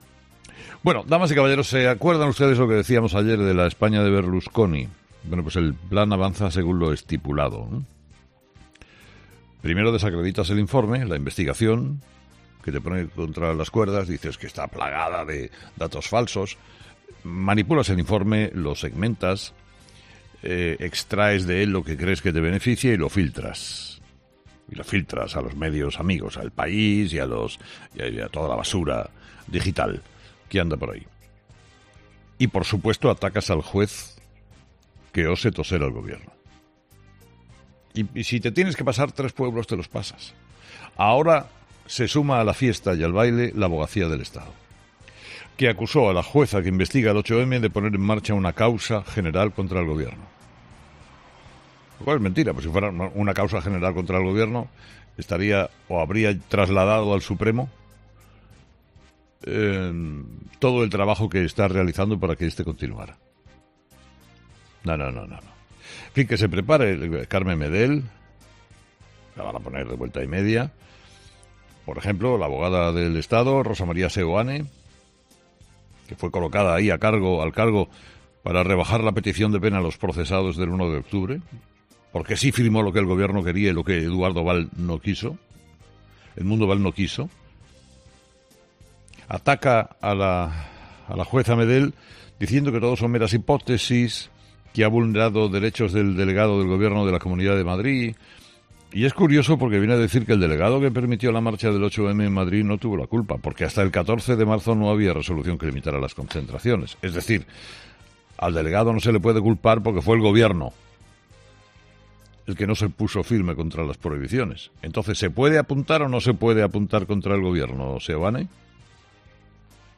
El director de ' Herrera en COPE' ha recordado en su monólogo de las 08.00 el altercado que enfrentó a la entonces portavoz popular Cayetana Álvarez de Toledo y Pablo Iglesias.